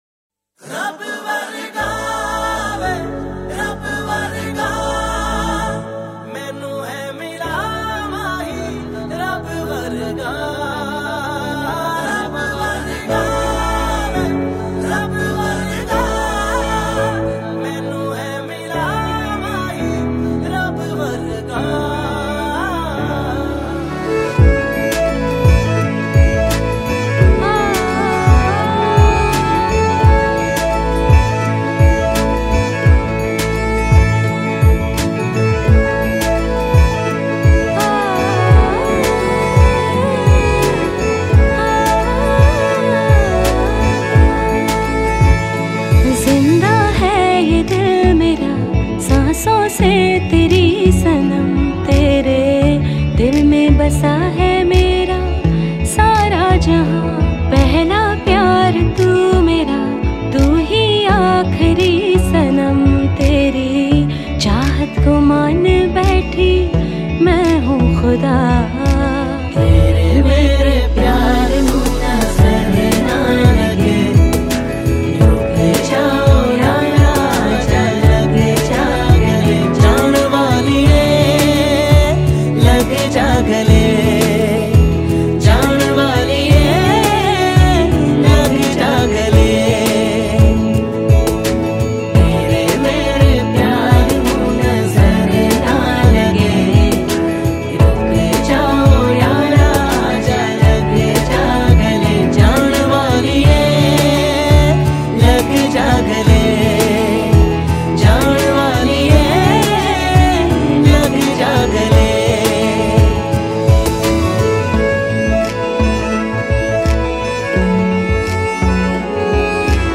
Bollywood Mp3 Music 2017